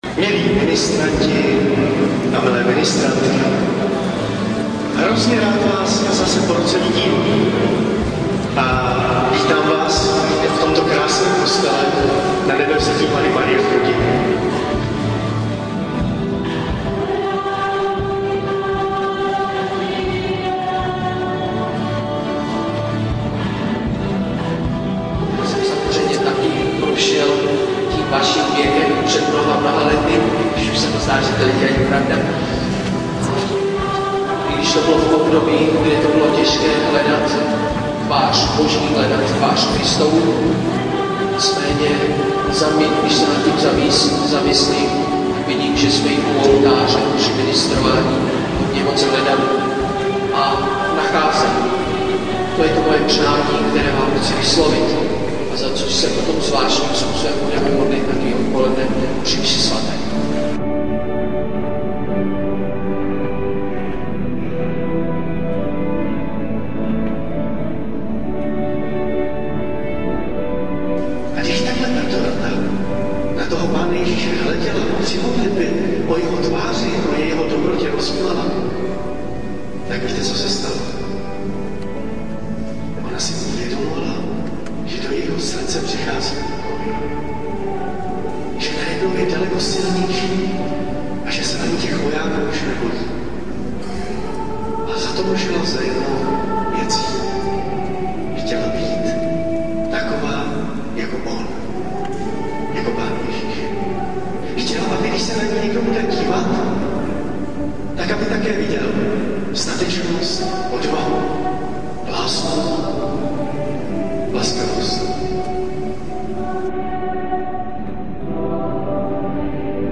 Sestřih z ministrantského setkání 2012
Jako podbarvení nabízíme nahrávku Hlubokého koření.